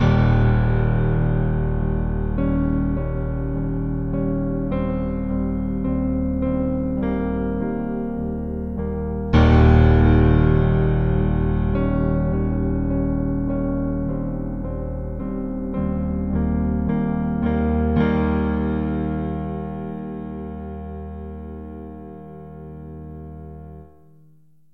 Some sad and dark piano tracks suited for short cutscenes.